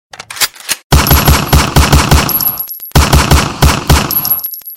Gun Sound Effect Free Download
Gun